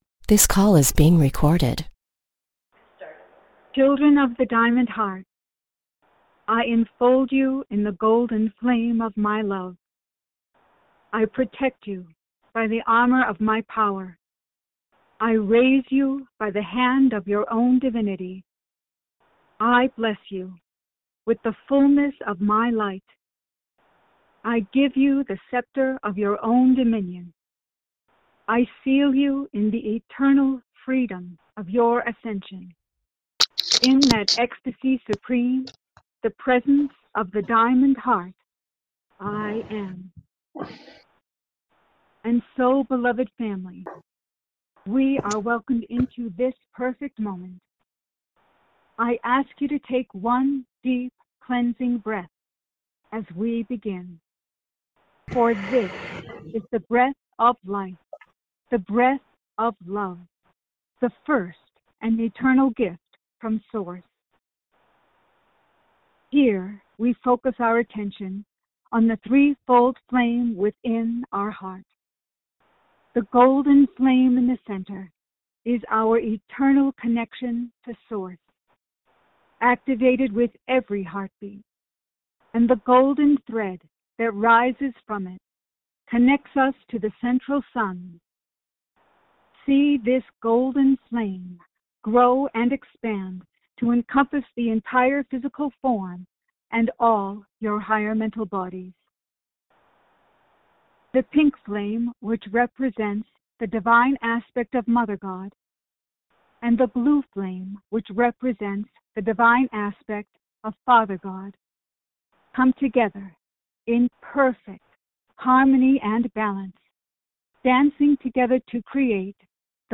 Click the “Audio Recording” link to follow along in group meditation with Archangel Michael.